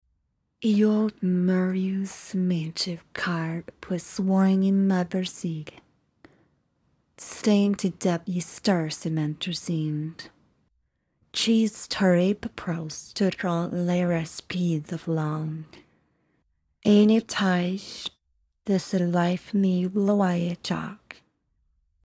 The young woman and reading an audiobook part are there, but it just can't generate vocal fry.
Attachment: a_woman_reading_an_a.mp3 (audio/mpeg)